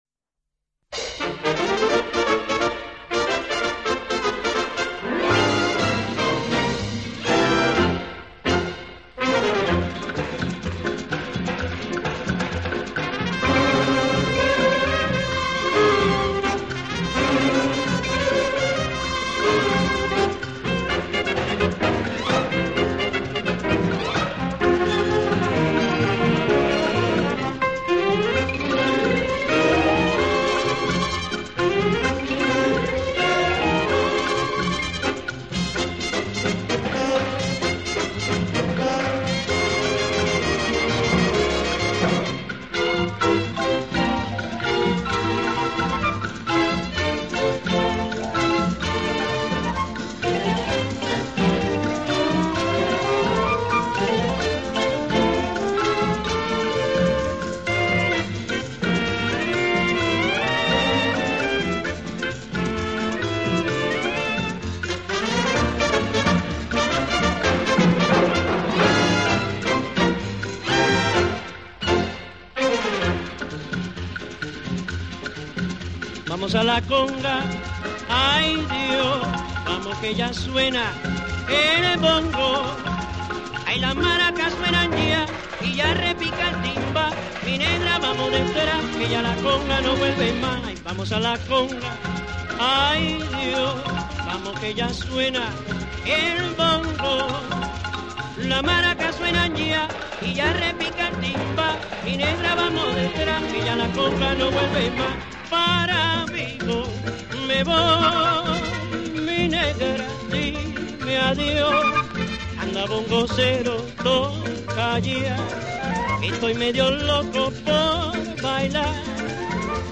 拉丁爵士